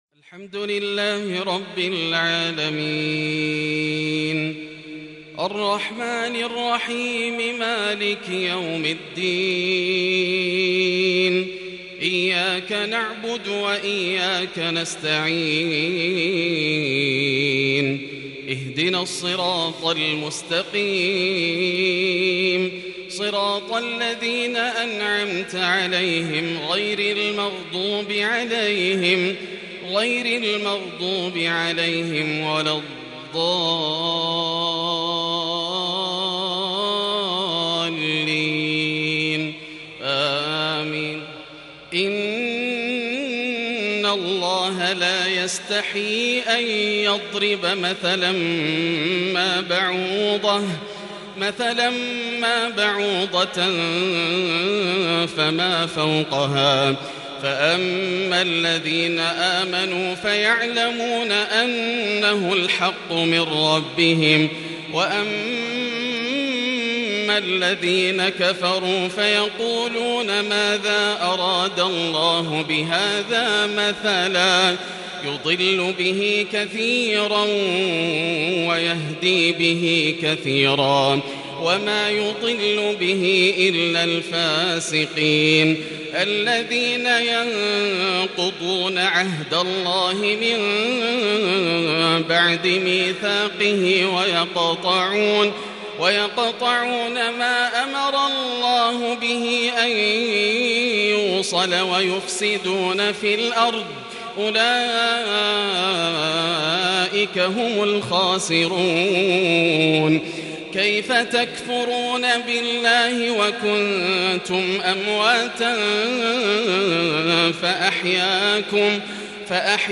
سورة البقرة | Maghrib prayer from Surat Al-Baqarah 24/8/2020 > 1442 🕋 > الفروض - تلاوات الحرمين